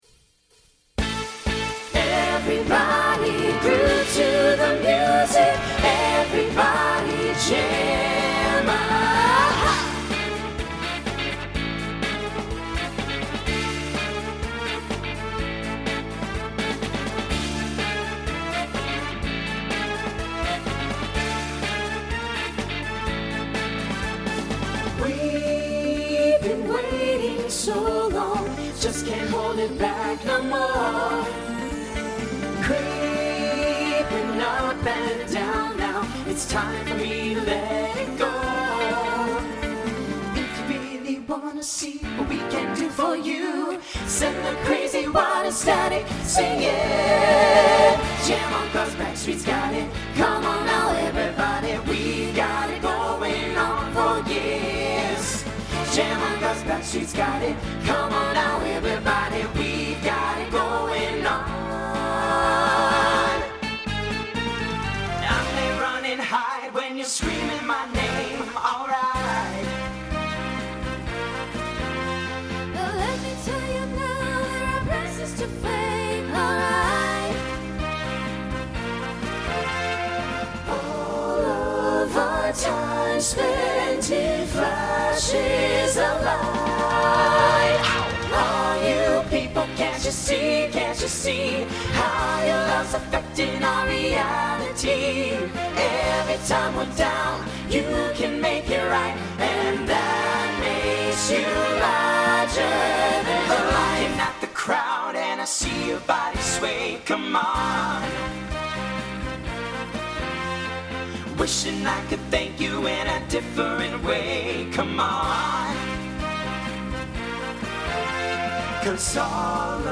SATB Instrumental combo
Pop/Dance